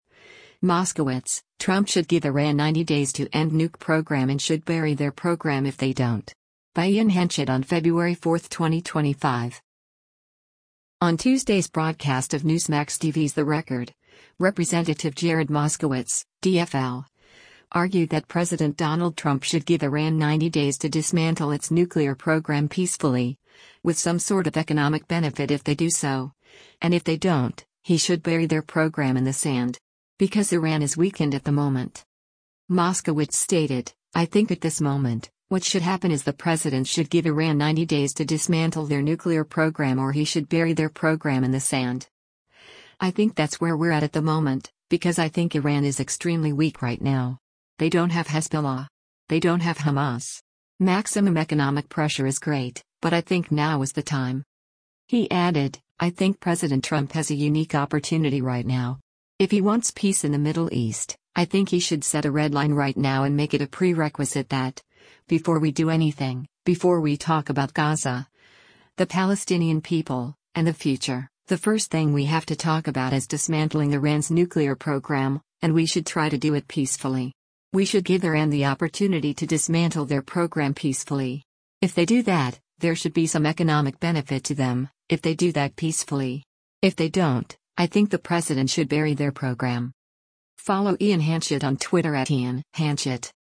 On Tuesday’s broadcast of Newsmax TV’s “The Record,” Rep. Jared Moskowitz (D-FL) argued that President Donald Trump should give Iran 90 days to dismantle its nuclear program peacefully, with some sort of economic benefit if they do so, and if they don’t, “he should bury their program in the sand.”